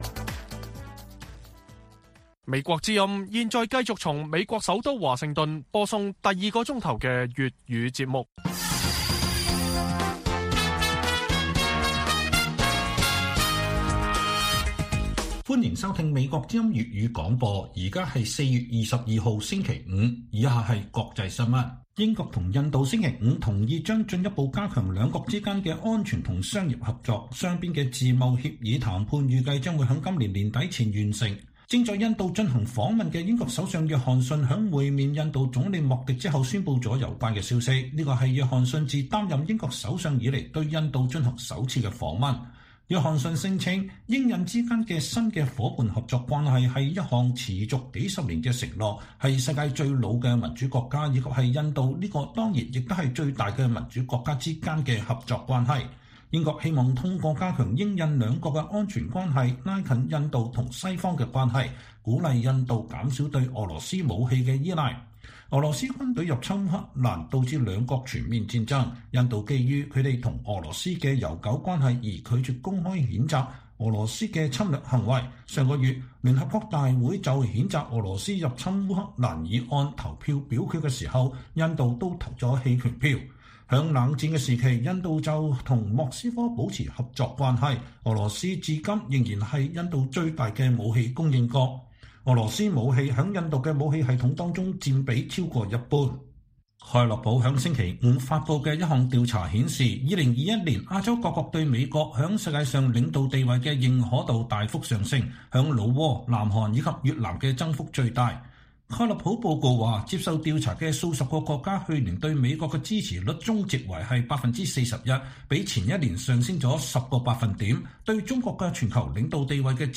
粵語新聞 晚上10-11點: 英國首相訪問新德里 爭取印度對抗“專制威脅”的增加